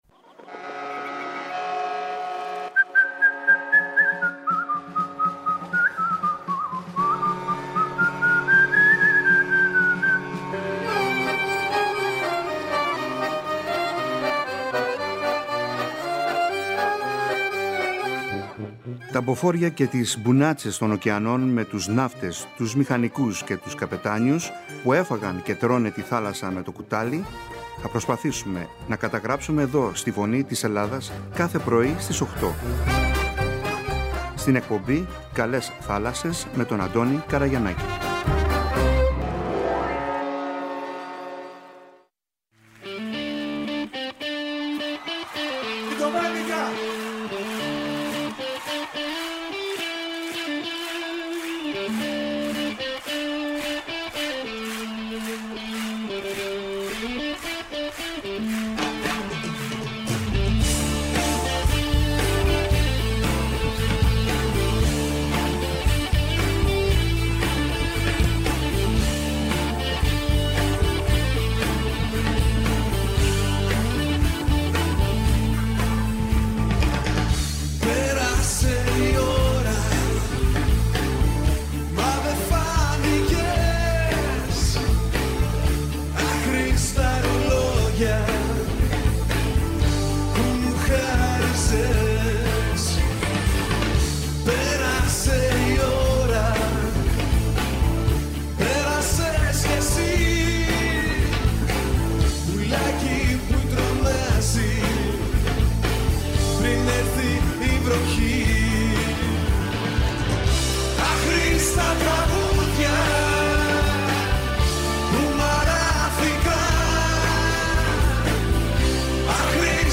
μίλησε στην εκπομπή «ΚΑΛΕΣ ΘΑΛΑΣΣΕΣ».